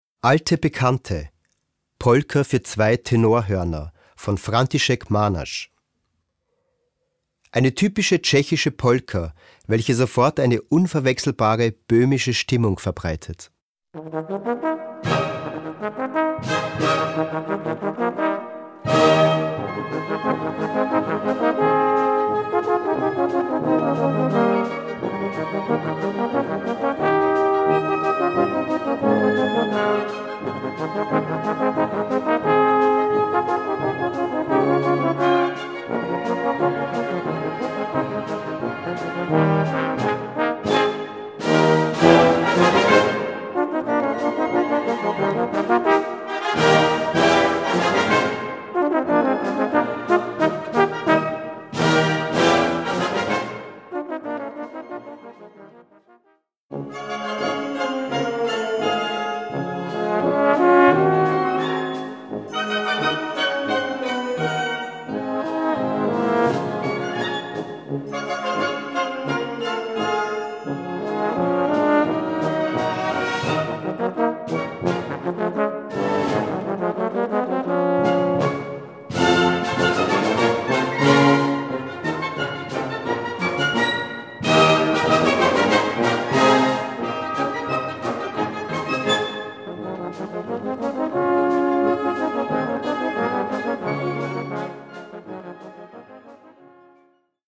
Gattung: Konzertpolka für 2 Posaunen in C/B
Besetzung: Blasorchester